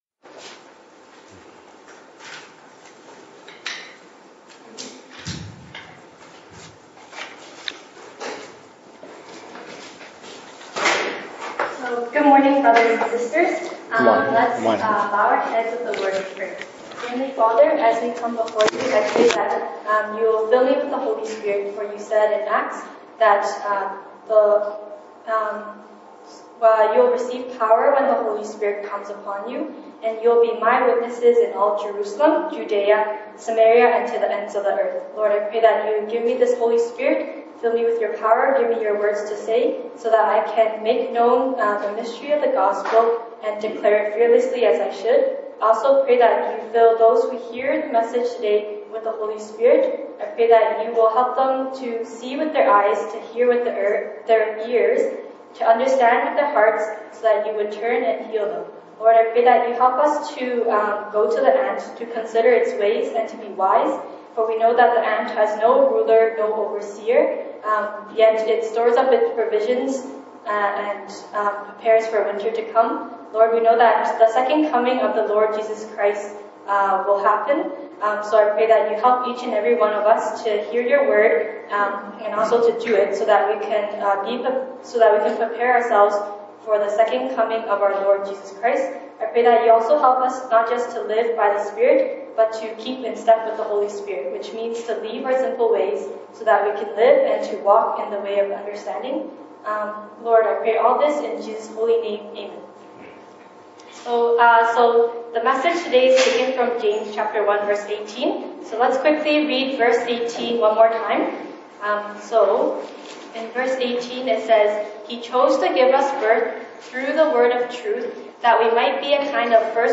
西堂證道 (英語) Sunday Service English: Function of the Word of Truth